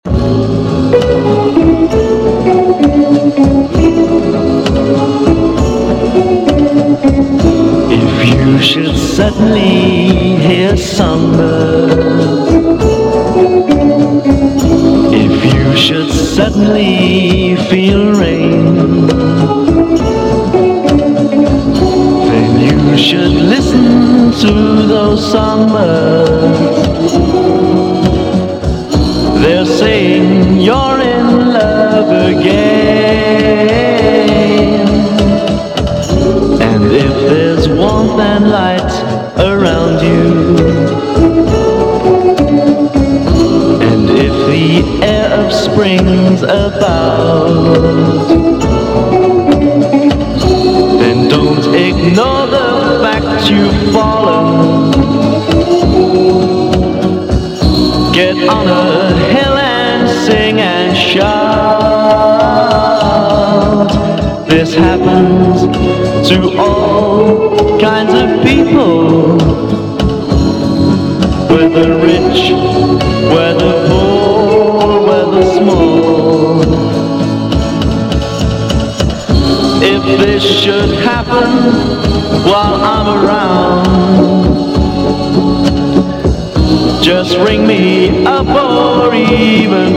ROCK / 60'S (JPN)